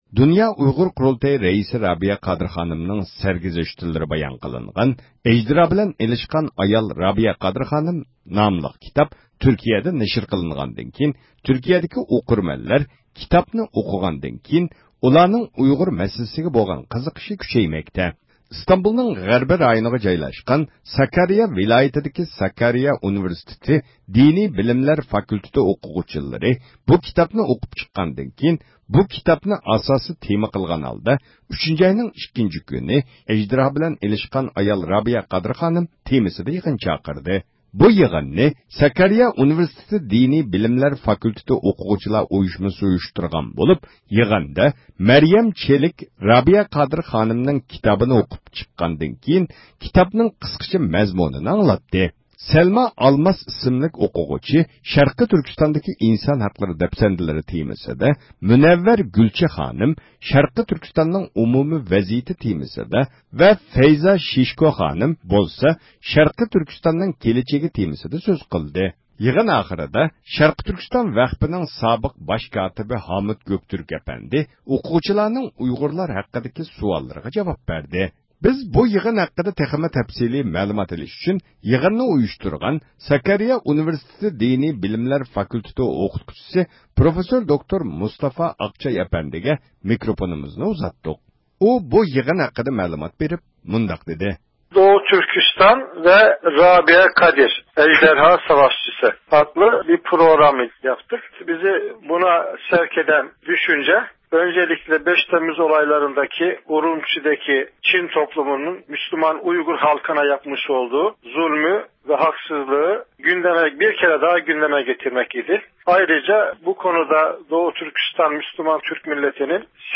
ئىختىيارىي مۇخبىرىمىز